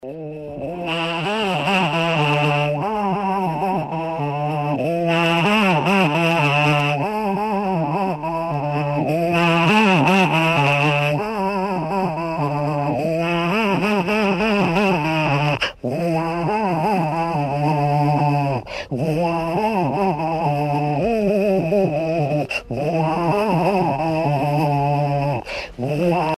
Jeu vocal Ubuhuha
voix travestie